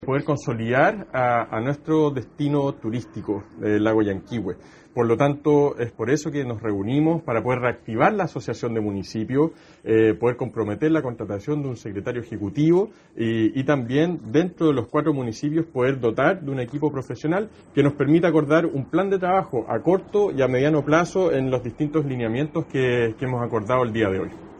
Tomás Garate, alcalde electo de Puerto Varas, explicó que se reunieron para poder reactivar la asociación de municipios, para acordar un plan de trabajo a corto y mediano plazo